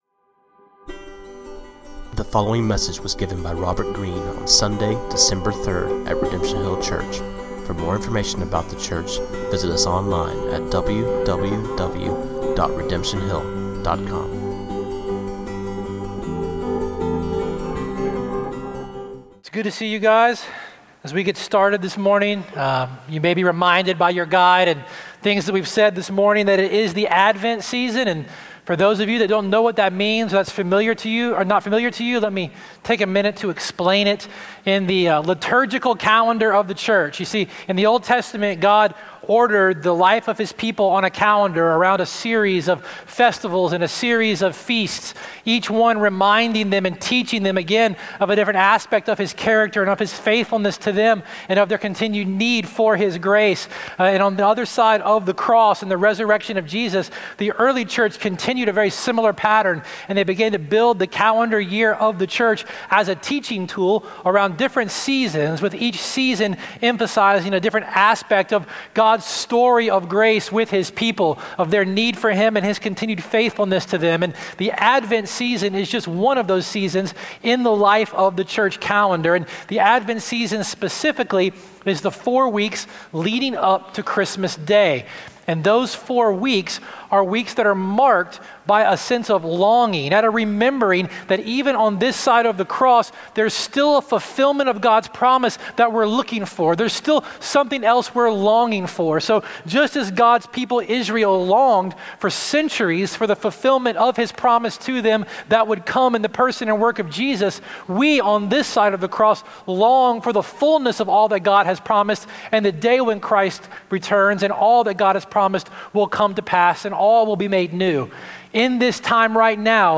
This sermon on Daniel 1:1-21